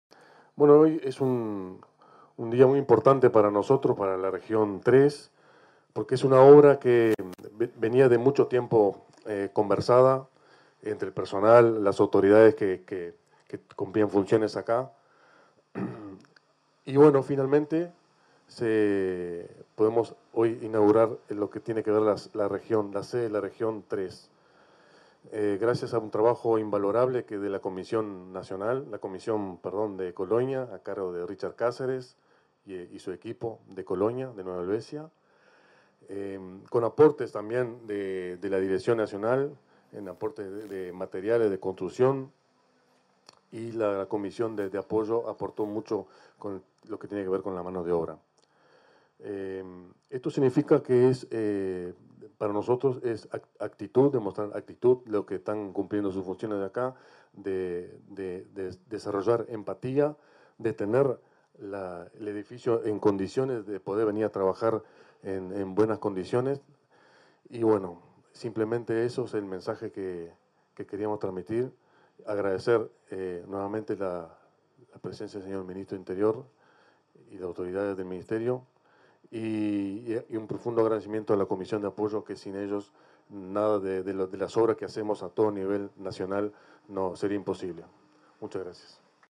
Palabras del director nacional de Policía Caminera, Mauricio Tort
Palabras del director nacional de Policía Caminera, Mauricio Tort 05/06/2024 Compartir Facebook X Copiar enlace WhatsApp LinkedIn En el marco del acto inaugural de la sede de la Región III, de la Dirección Nacional de Policía Caminera, este 5 de junio, se expresó el director de la última dependencia citada, Mauricio Tort.